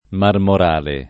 [ marmor # le ]